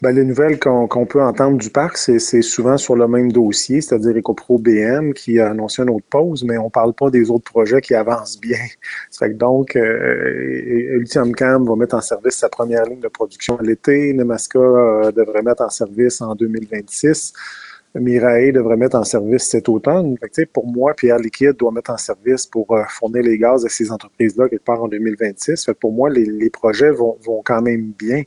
En entrevue, le président-directeur général a soutenu qu’il y avait aussi de très bonnes nouvelles, mais que celles-ci passaient souvent sous le silence.